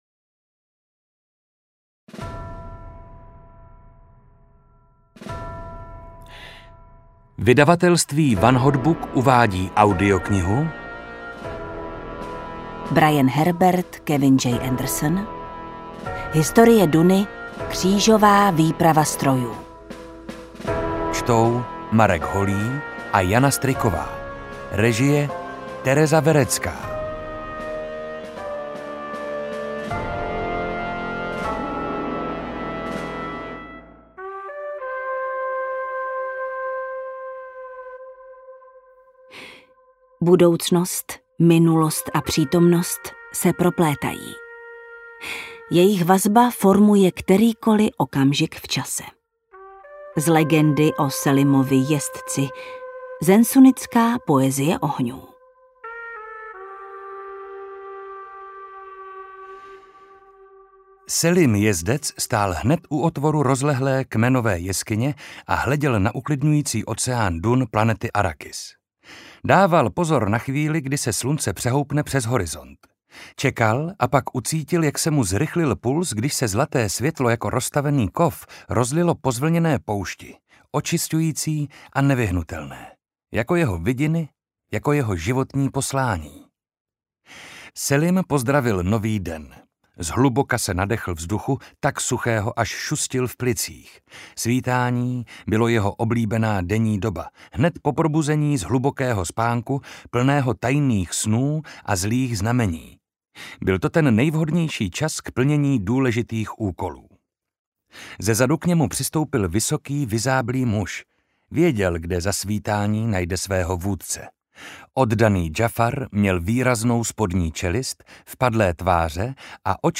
Ukázka z knihy
• InterpretJana Stryková, Marek Holý